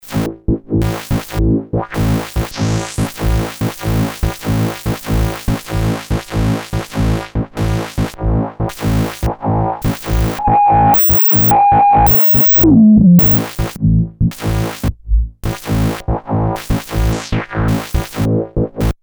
various FX 5
noise6.mp3